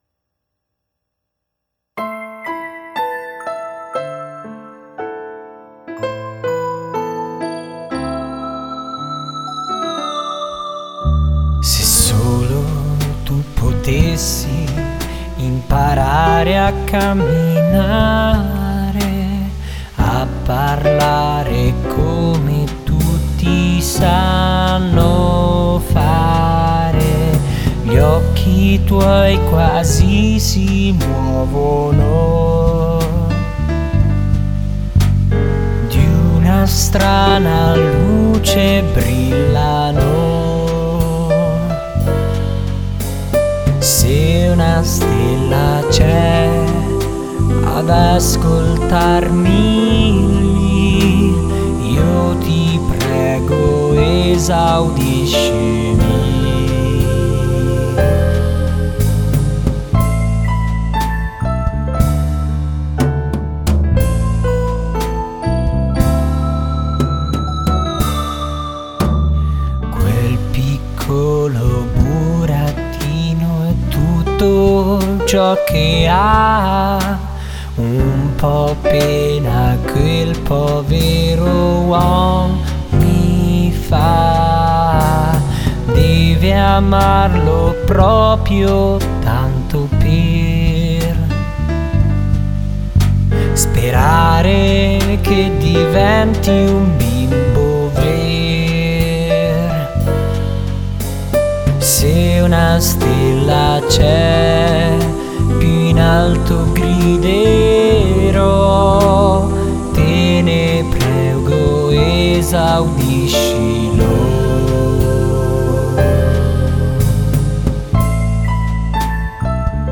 Il risultato è una commedia musicale per bambini con i toni, le caratteristiche stilistiche e musicali di un vero musical.